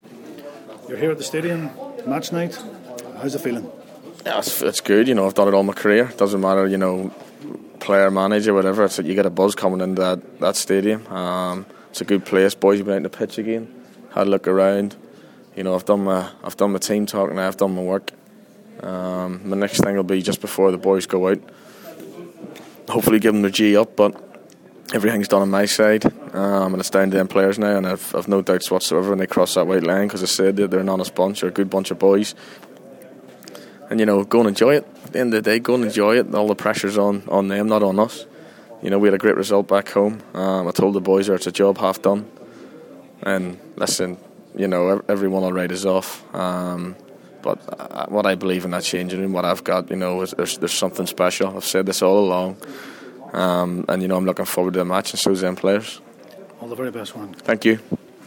Warren Feeney in AIK stadium